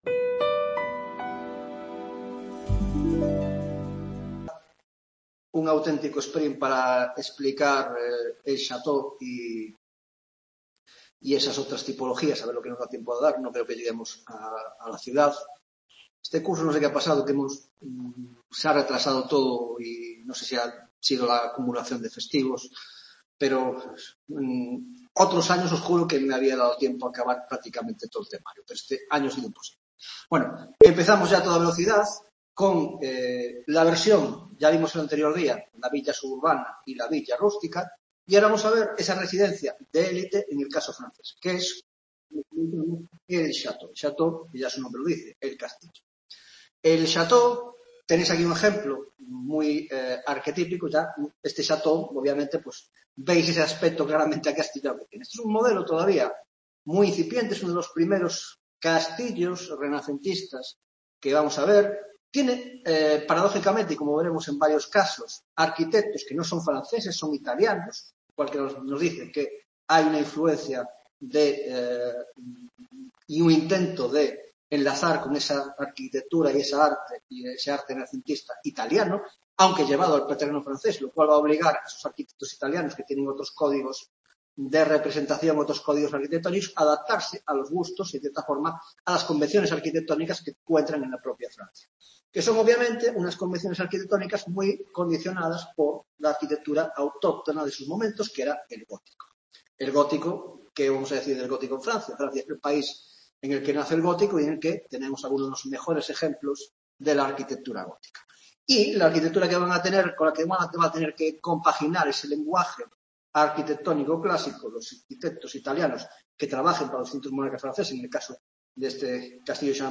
10ª Tutoría de Órdenes y Espacio en la Arquitectura Moderna (grado de Historia del Arte): Tipología: 1) El Château, francés y Los Palacios Reales; 2) El Jardín, 3) Otras tipologías: la Biblioteca, el Hospital y el Teatro.